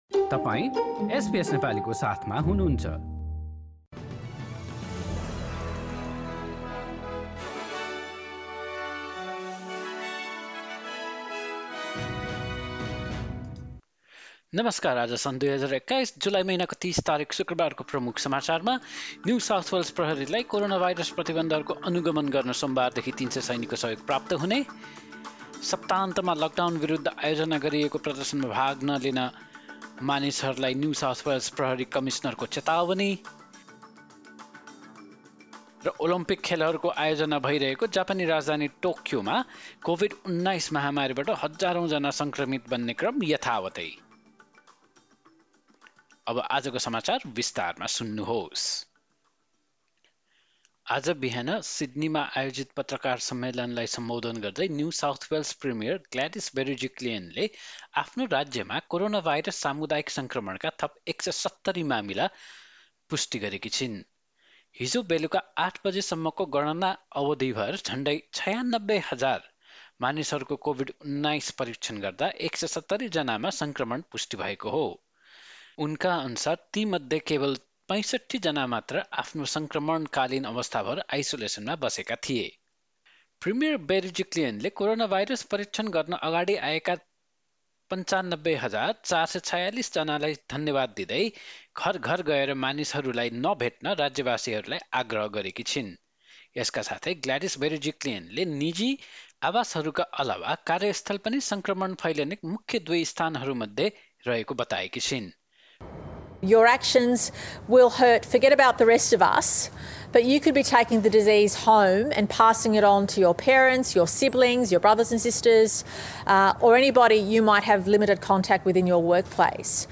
एसबीएस नेपाली अस्ट्रेलिया समाचार: शुक्रवार ३० जुलाई २०२१